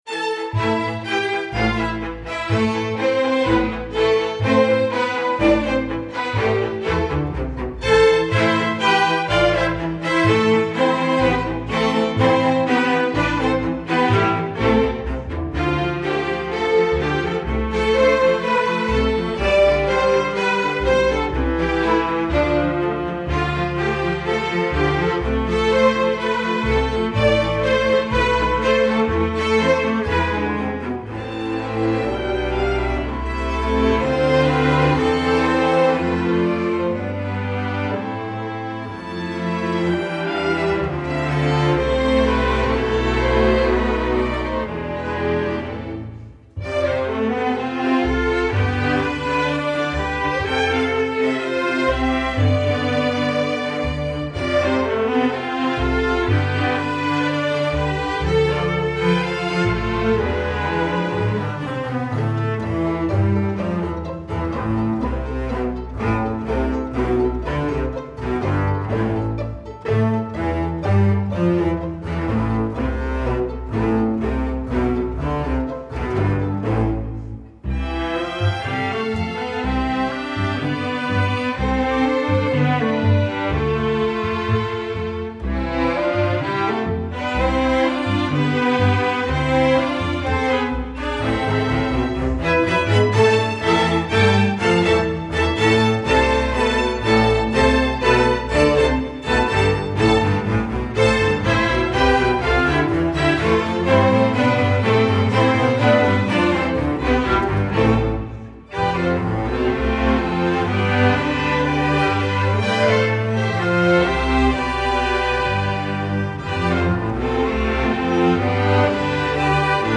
film/tv, movies, instructional